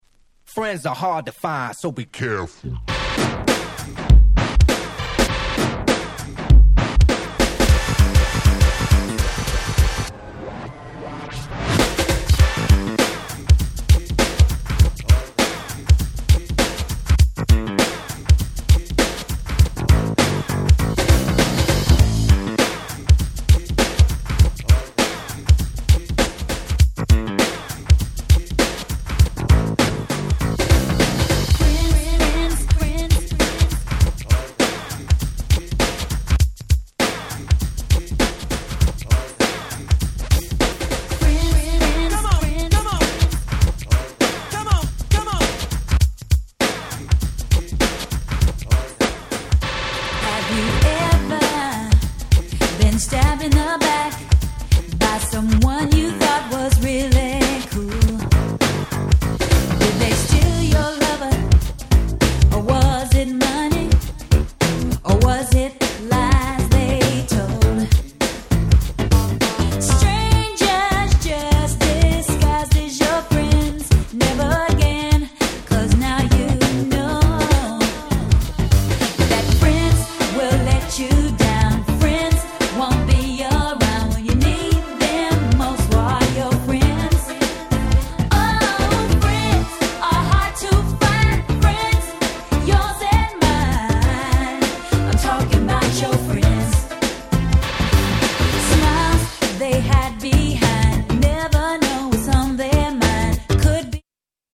New Jack Swing Classic !!
恥ずかしいくらいハネてます！